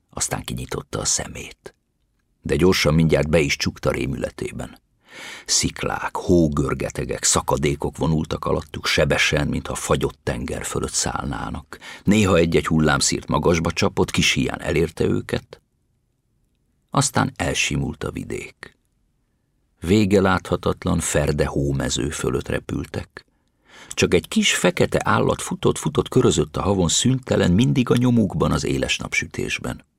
Album: Hangoskönyvek gyerekeknek